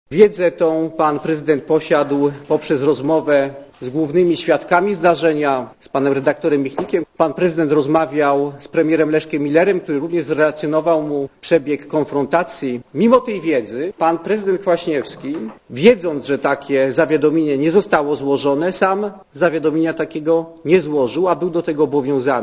Posłuchaj wypowiedzi Zbigniewa Ziobry